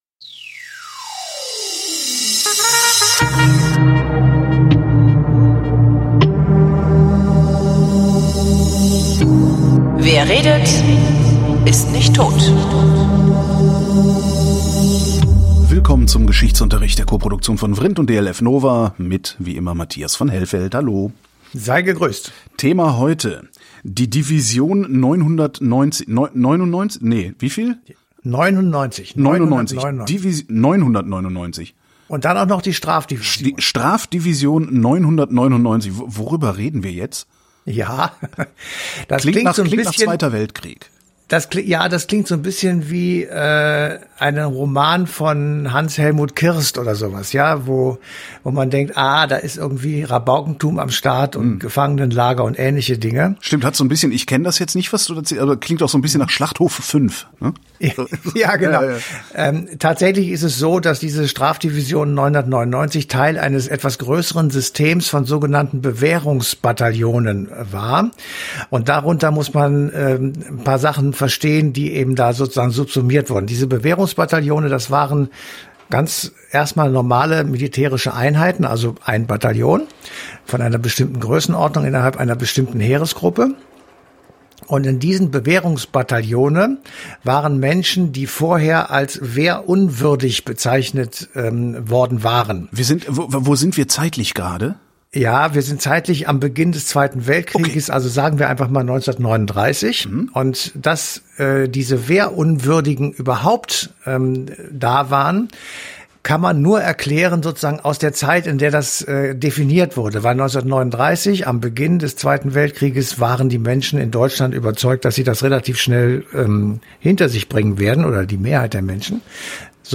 wrint: gespräche zum runterladen